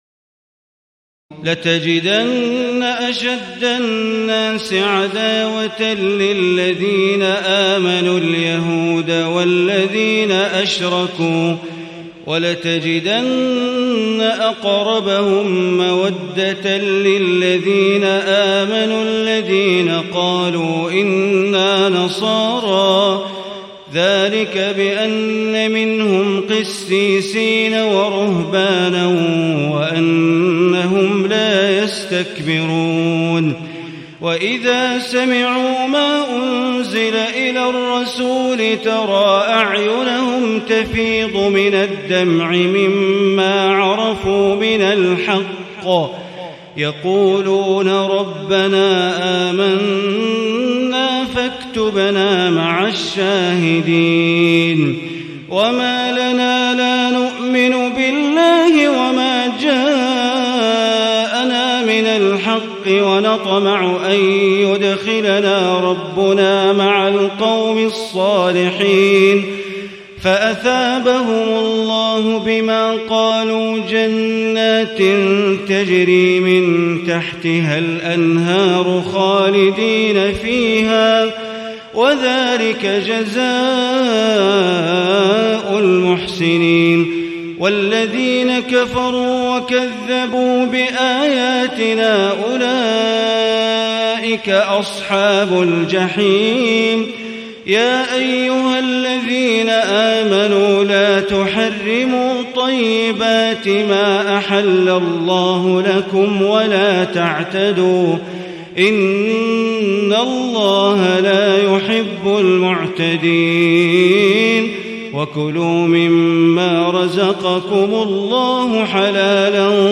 تهجد ليلة 27 رمضان 1437هـ من سورتي المائدة (82-120) و الأنعام (1-58) Tahajjud 27 st night Ramadan 1437H from Surah AlMa'idah and Al-An’aam > تراويح الحرم المكي عام 1437 🕋 > التراويح - تلاوات الحرمين